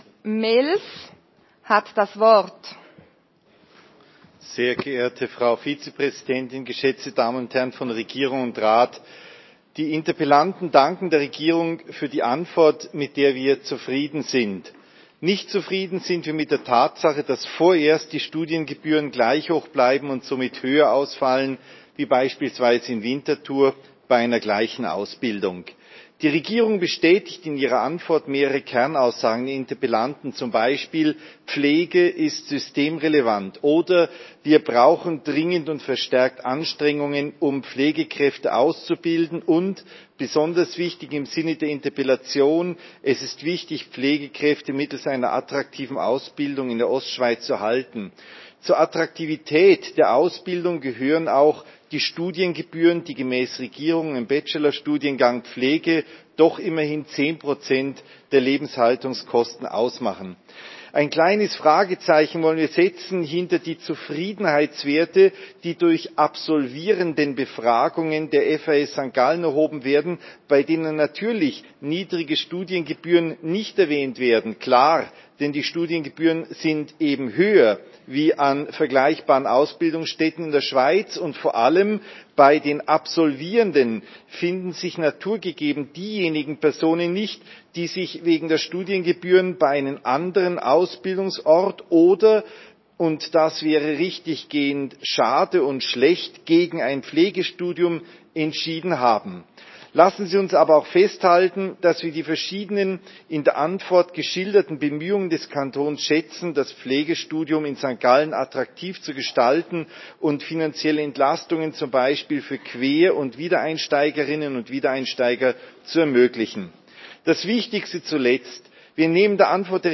14.9.2020Wortmeldung
Session des Kantonsrates vom 14. bis 17. September 2020